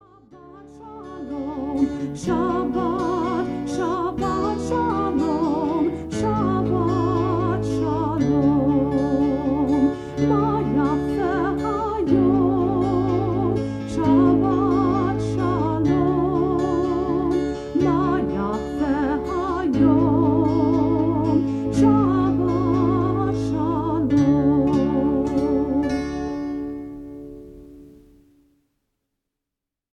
(Folk)